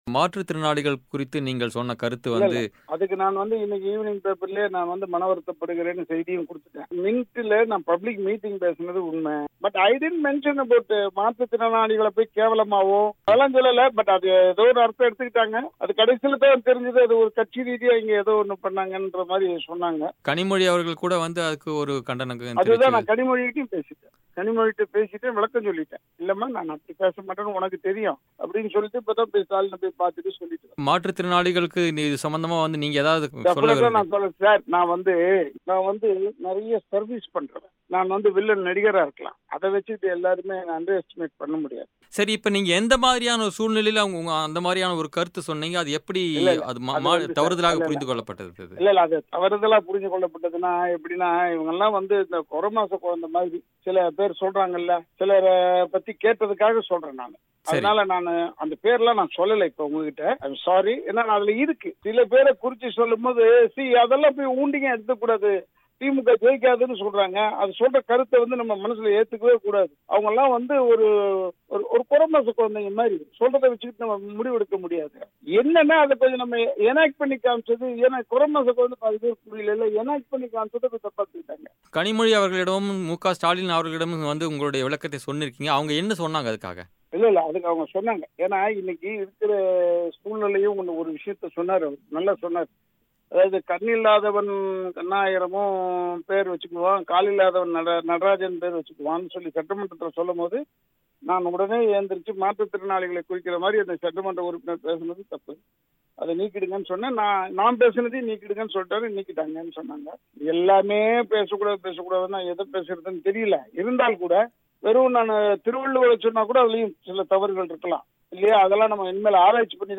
இந்நிலையில், தான் எப்படிப்பட்ட சூழ்நிலையில் அவ்வாறு பேசினார் என்பது குறித்து பிபிசி தமிழுக்கு ராதா ரவி அளித்த பேட்டி.